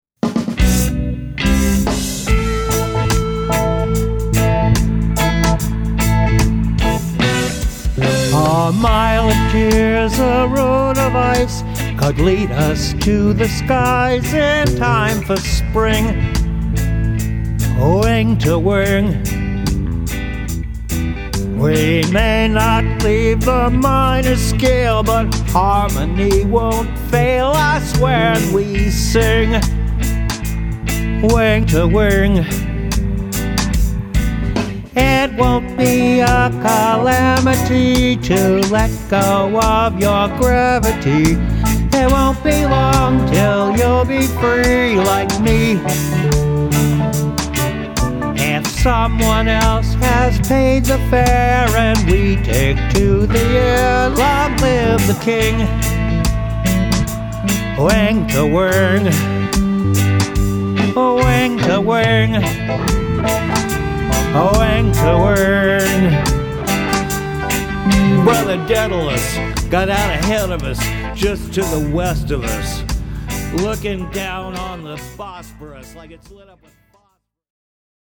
reggae song